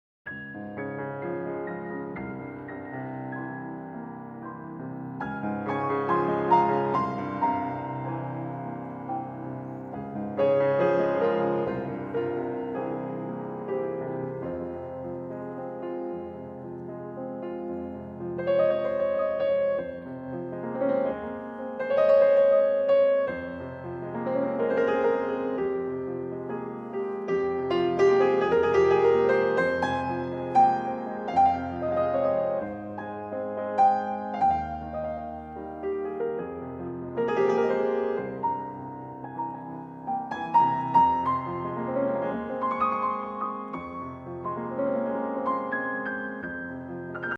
Original music for acoustic solo piano
Solo Piano pieces (Detailed descriptions)